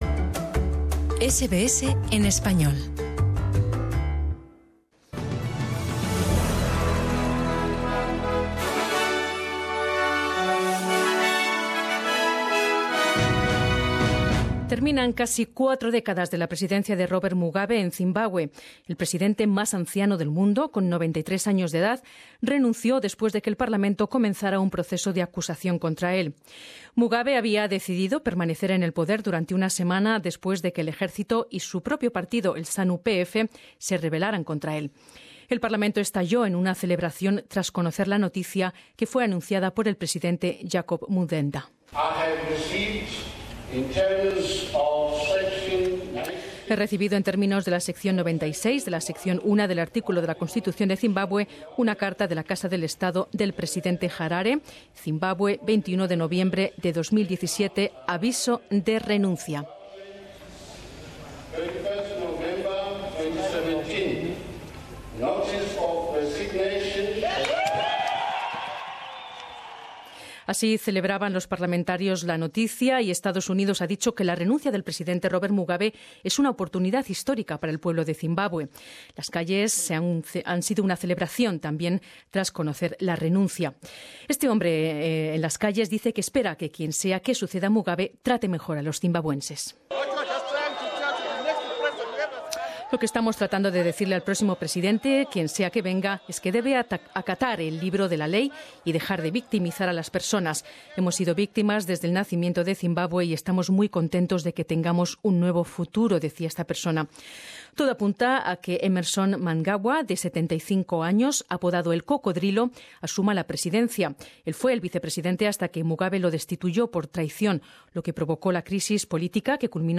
Escucha las noticias de Australia en el Informativo de SBS en español 22 Nov